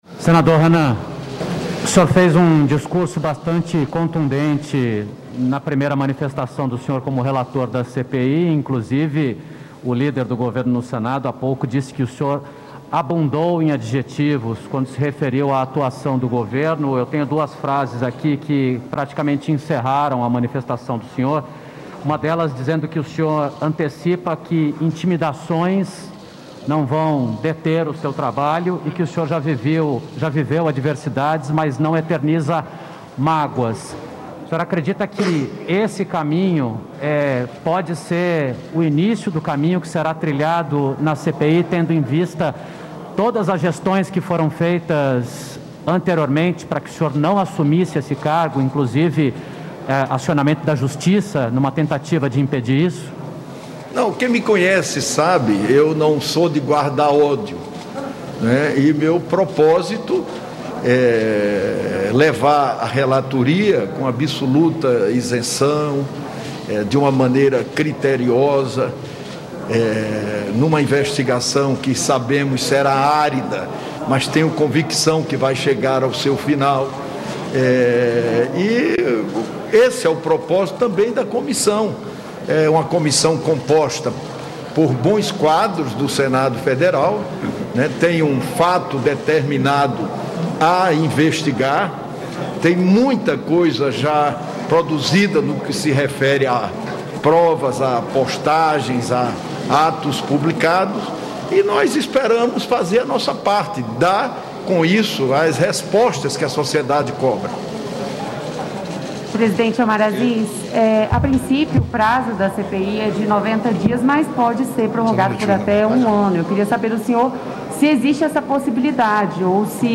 Assim que acabou a reunião de instalação da CPI da Pandemia, os senadores Omar Aziz (PSD-AM), Randolfe Rodrigues (Rede-AP) e Renan Calheiros (MDB-AL), que vão comandar os trabalhos, concederam entrevista coletiva. Renan Calheiros disse que as prioridades da CPI serão encontrar caminhos para destravar a aquisição de vacinas e saber se alguma autoridade concorreu para as mortes, seja por ação ou por omissão.